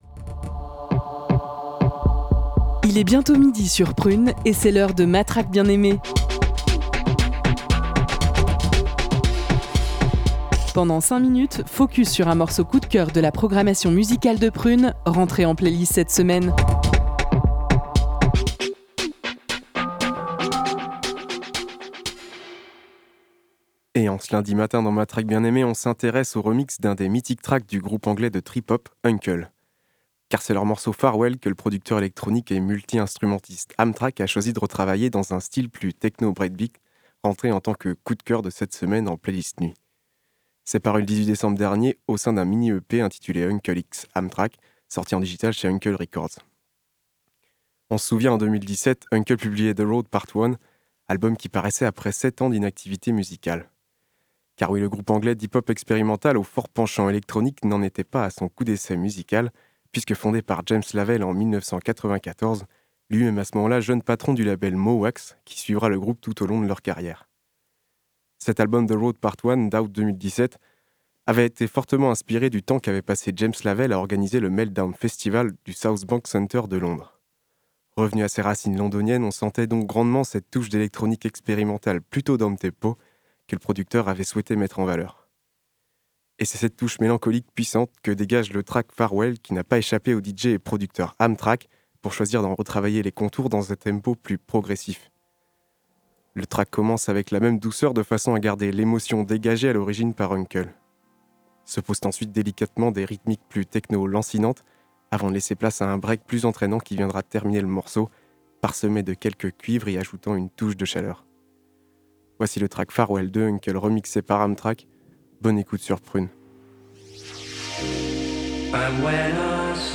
remix
trip-hop
techno/breakbeat